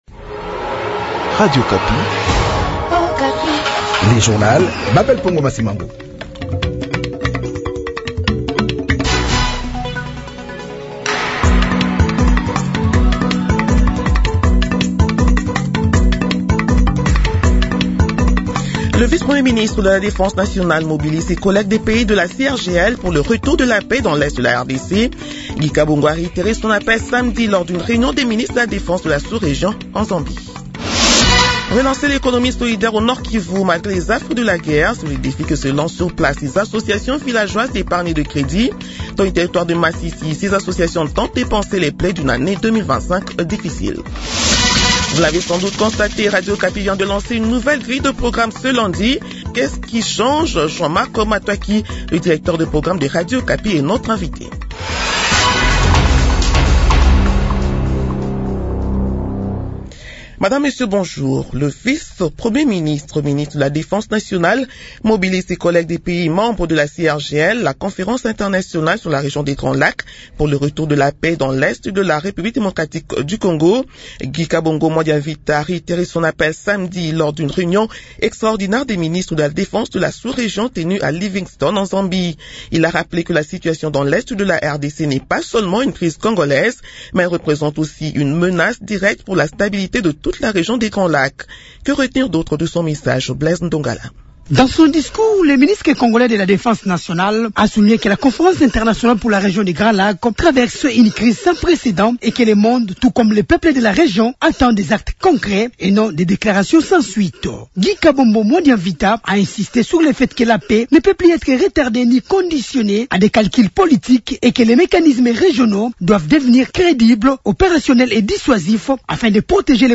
Journal 6h de ce lundi 12 janvier 2026